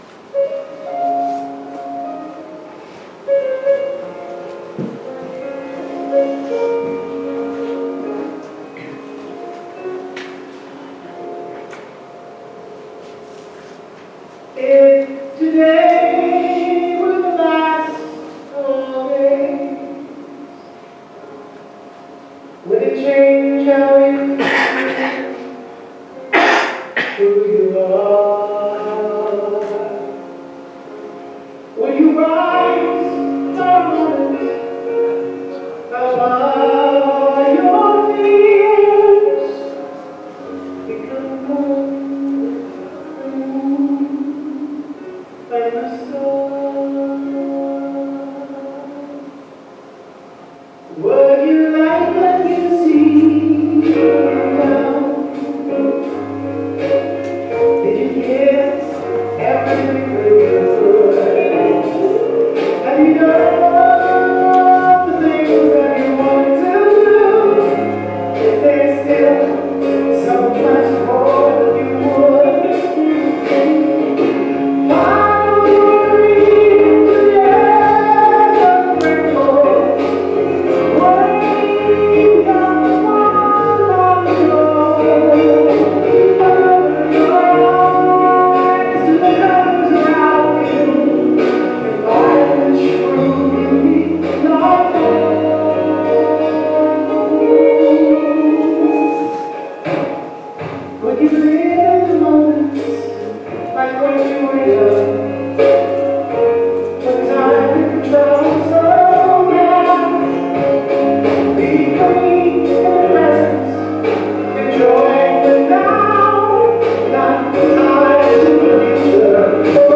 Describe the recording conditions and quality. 05/05/24 Sunday service